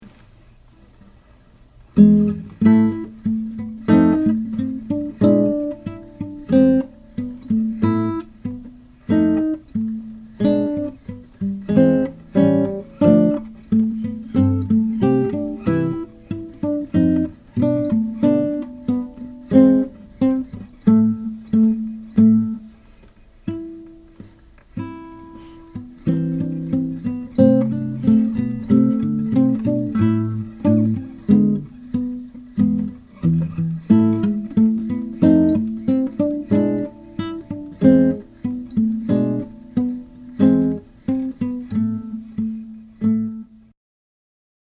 pizzicato